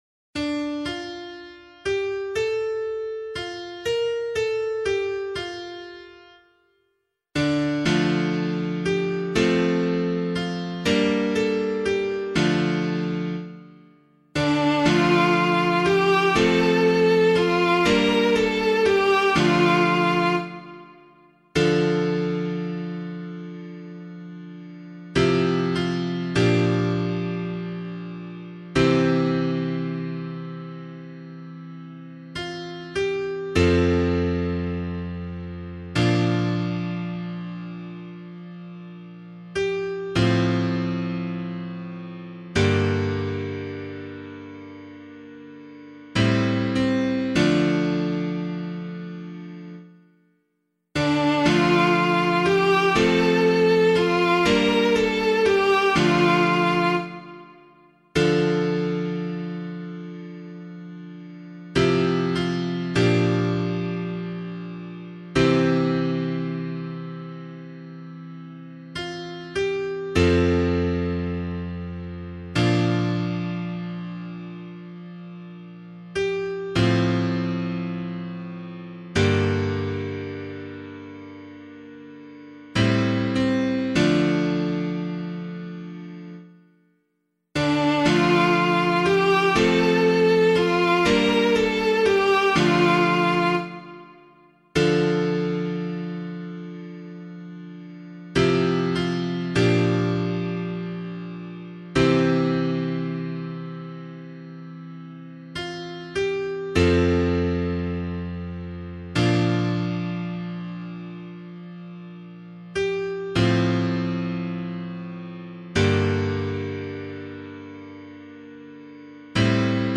011 Baptism of the Lord Psalm A [APC - LiturgyShare + Meinrad 1] - piano.mp3